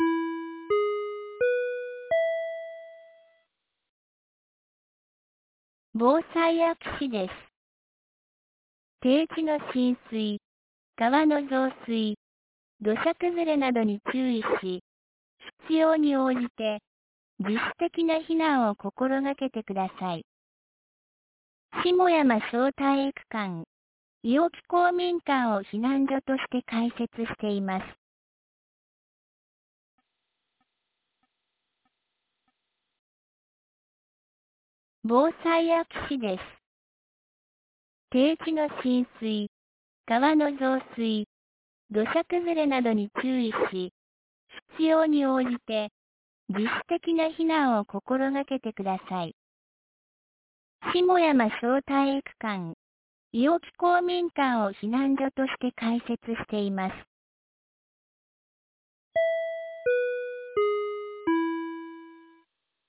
2023年06月02日 10時36分に、安芸市より伊尾木、下山へ放送がありました。
放送音声